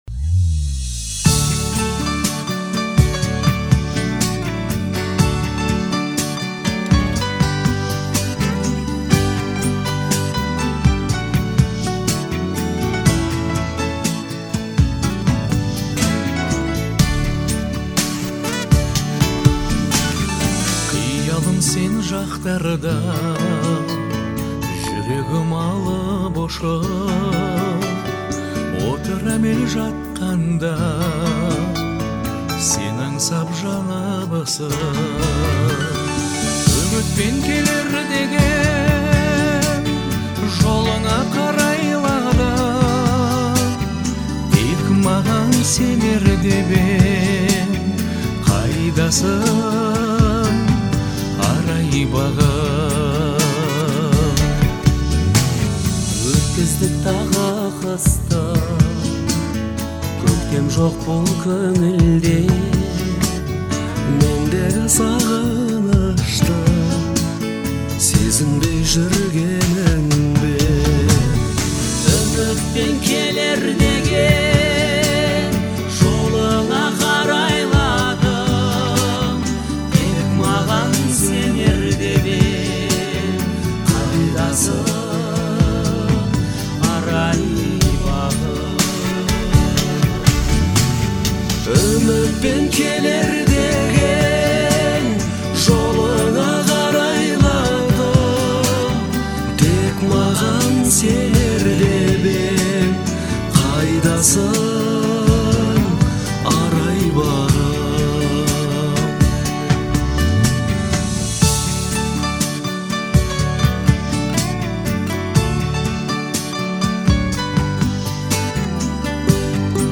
мелодичный вокал и гармоничное звучание